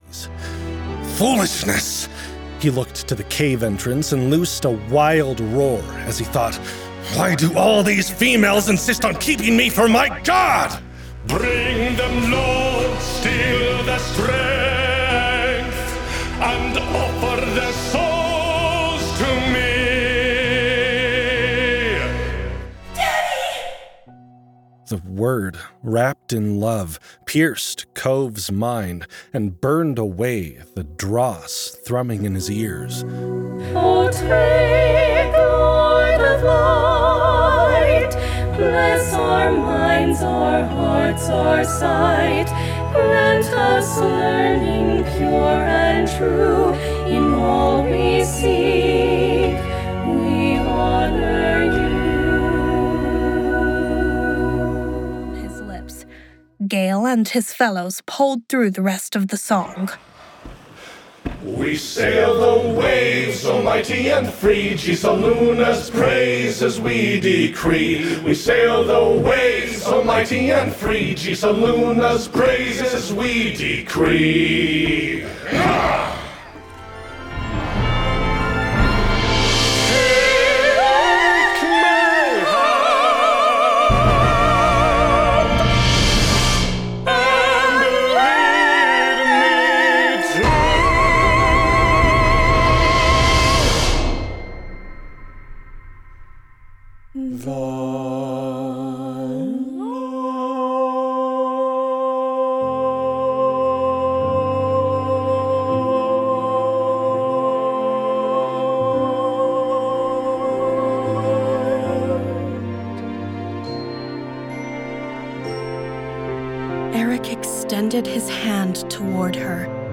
Sea shanties, opera, and bards—oh my!
Our duet productions—a male & female narrator—are perfect for books that are highly immersive and/or have perspective characters of multiple genders.